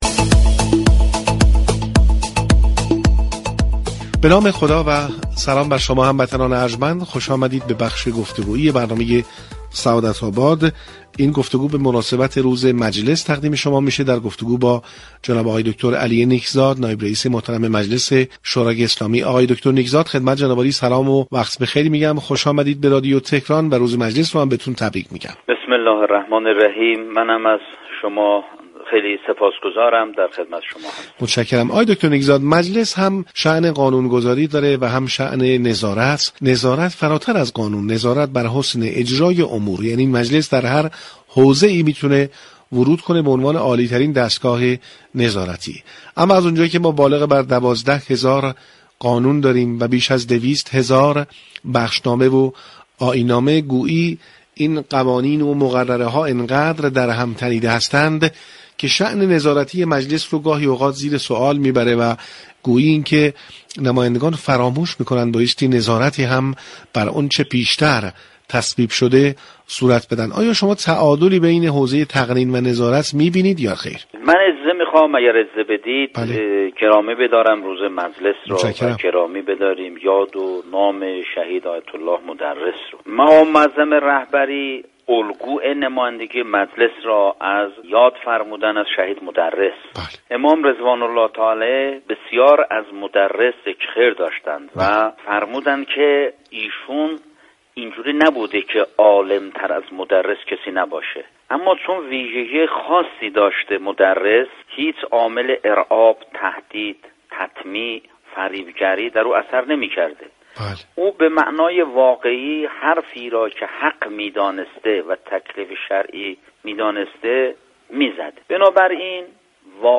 علی نیكزاد نایب رئیس مجلس یازدهم به مناسبت روز مجلس با برنامه سعادت آباد رادیو تهران گفتگو كرد.